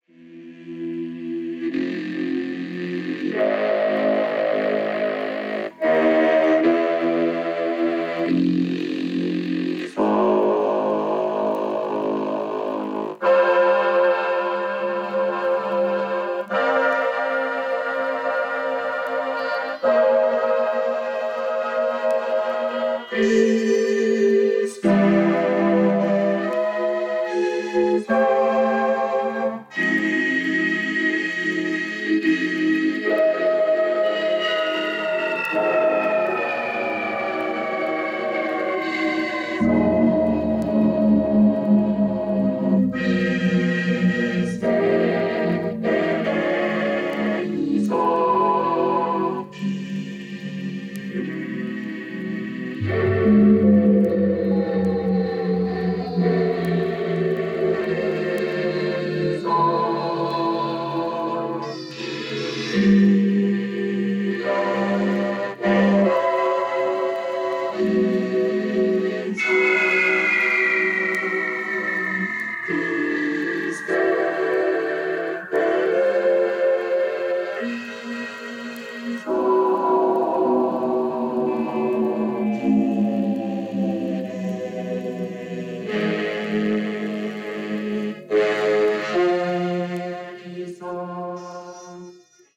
Synthesizer奏者の共演！！！